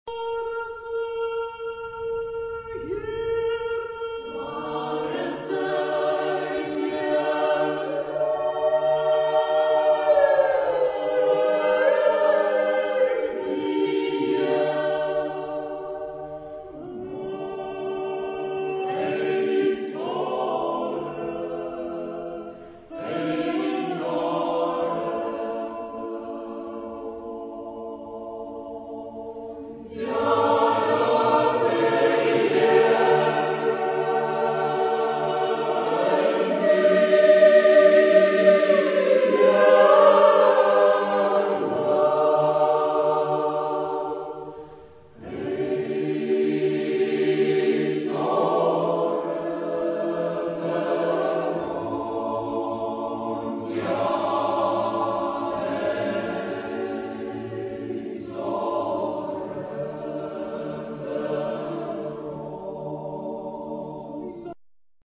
Genre-Style-Forme : Folklore ; Complainte ; Profane
Caractère de la pièce : librement ; calme ; lent
Type de choeur : SATB  (4 voix mixtes )
Solistes : Soprano (1) OU Tenor (1) OU Countertenor (1)  (1 soliste(s))
Tonalité : ré majeur